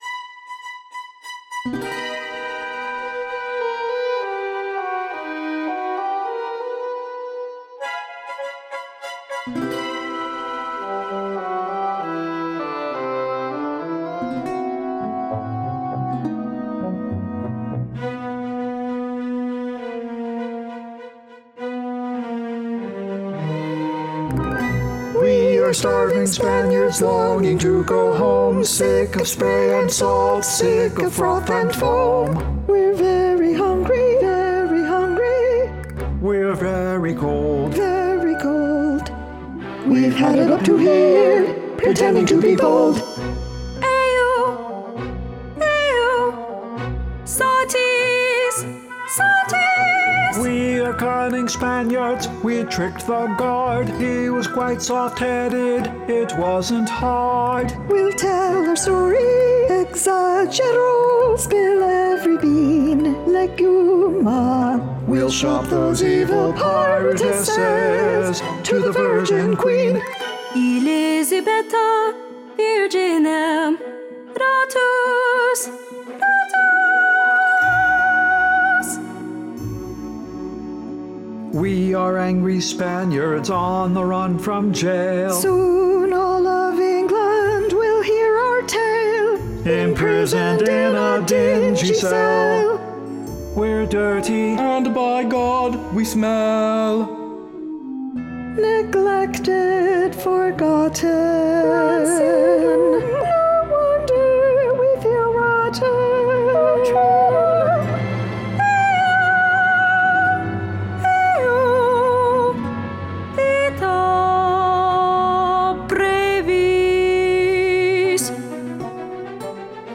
orchestration added 2/28/20
CC_6A-EscapeFromArwenack-rev1-demo-orch.mp3